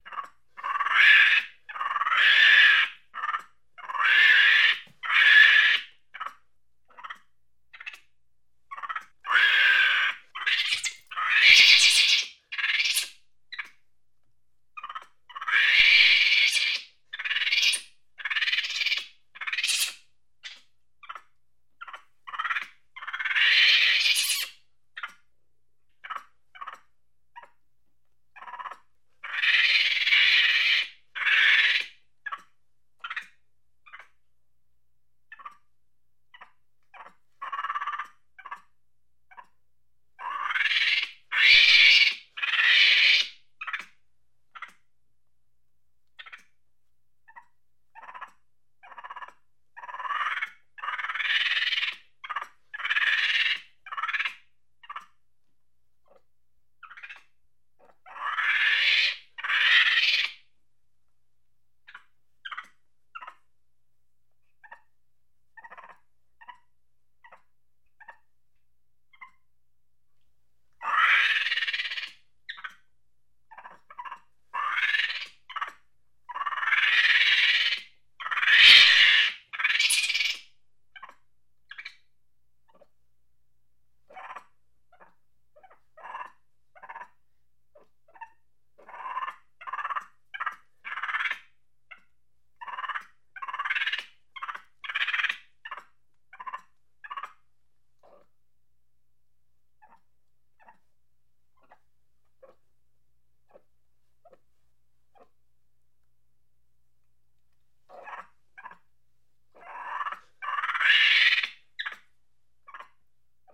Monkey sound - Eğitim Materyalleri - Slaytyerim Slaytlar